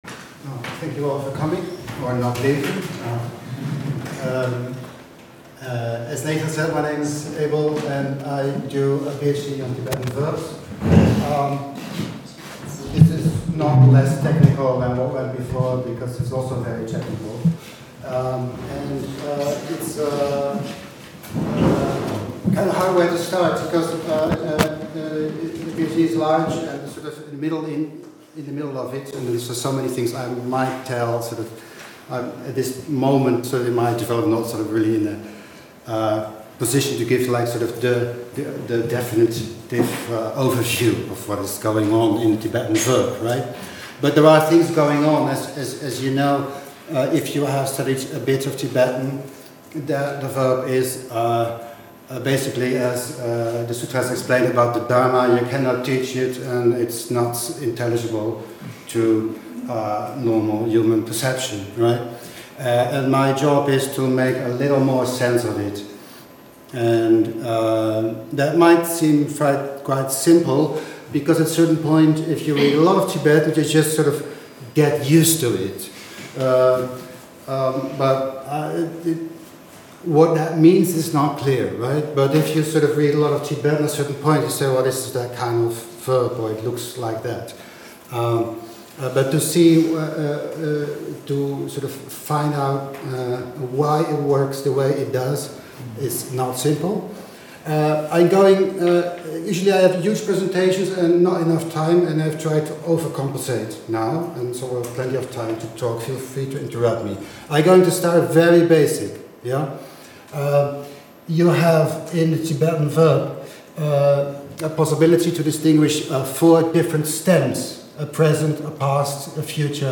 Remarks on Tibetan verbal morphology [Lecture given as part of Tibetan in Digital Communication Project, 21st March 2014]